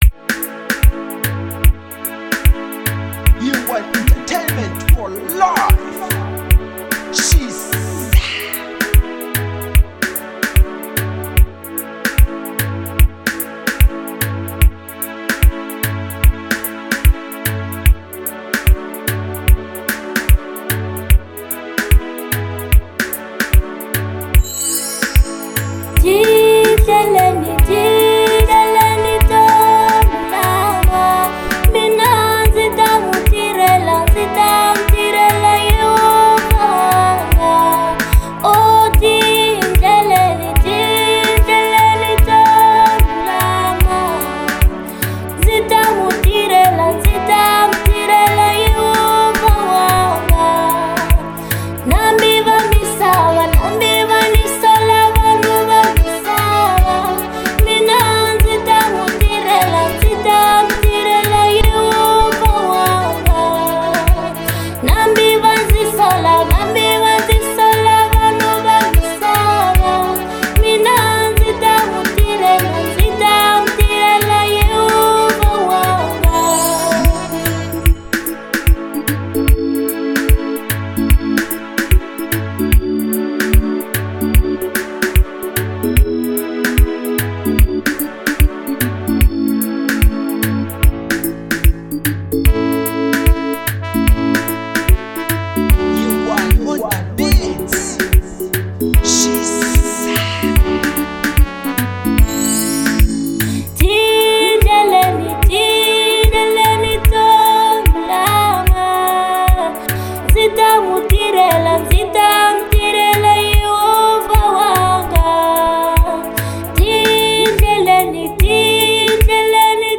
Genre : Gospel